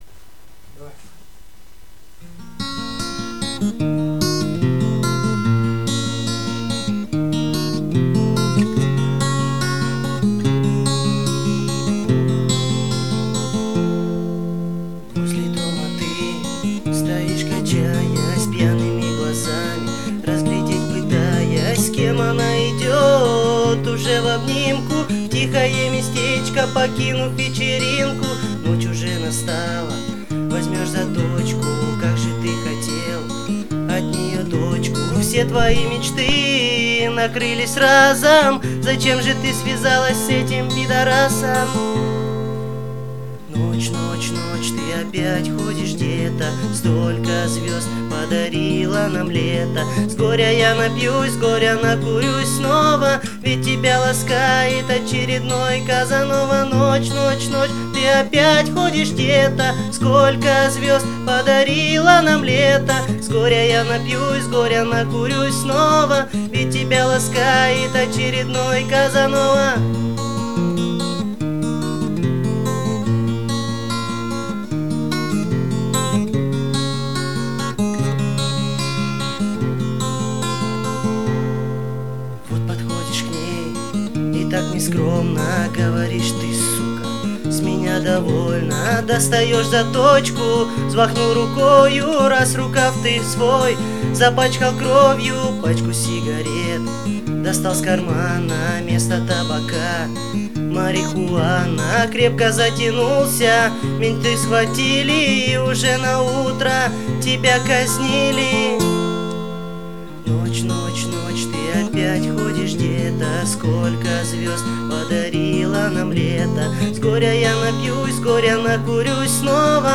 Категория: Песни под гитару